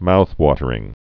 (mouthtə-rĭng)